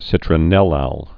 (sĭtrə-nĕlăl)